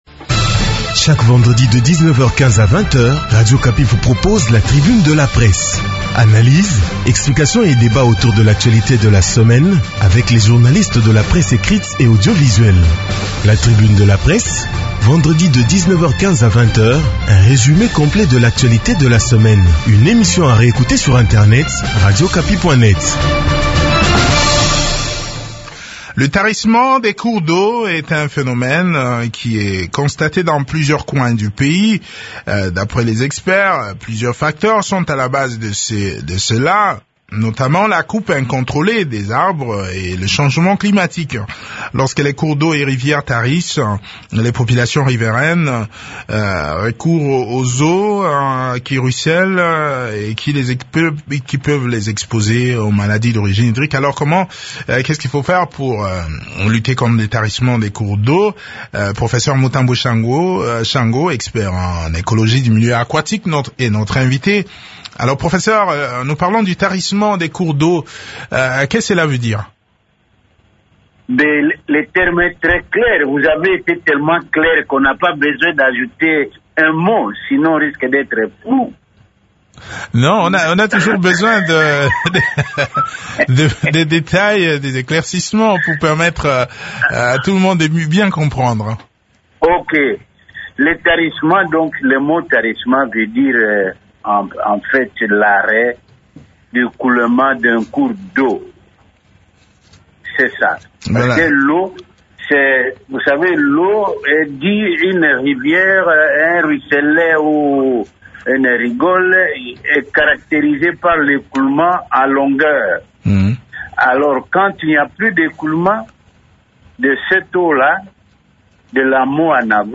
spécialiste en écologie du milieu aquatique.